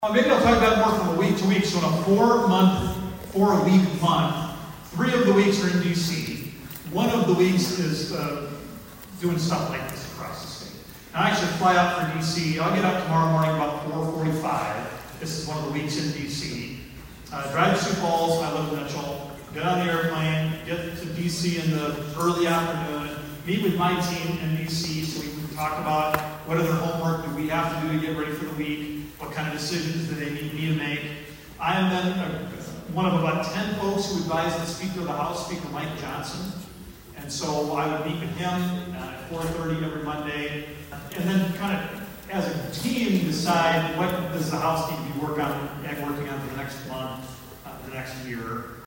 ABERDEEN, S.D.(HubCityRadio)- Monday kicked off the 82nd Boys State taking place at Northern State University in Aberdeen.